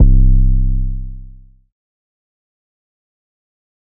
Metro Mad 808 (C).wav